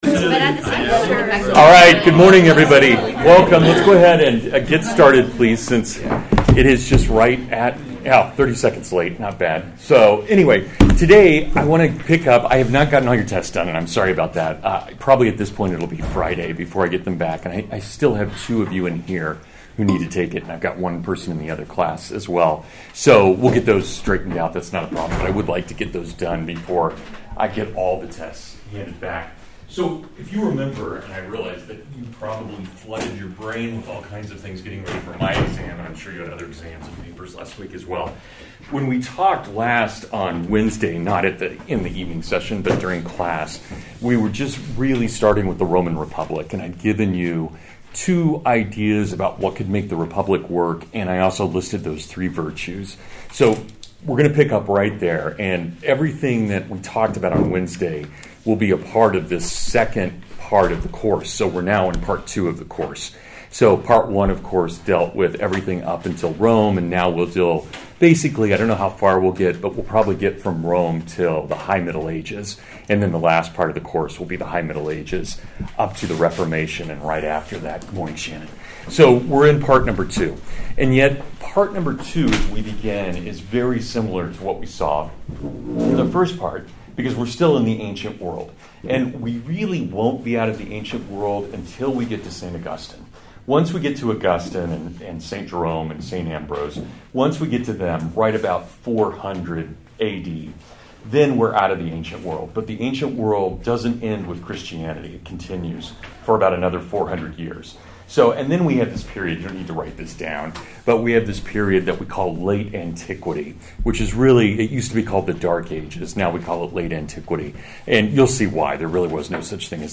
Roman Republic (Lecture)